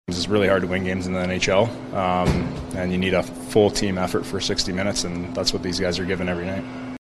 Goaltender Stuart Skinner is 5-1 after being acquired from Edmonton and will likely not be in goal tonight at his former home arena.  He says the Penguins are a hard-working group.